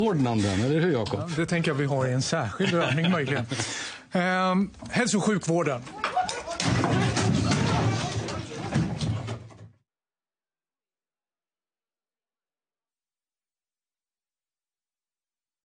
שרת הבריאות של שוודיה אליזבט לאן מתמוטטת היום בשידור חי.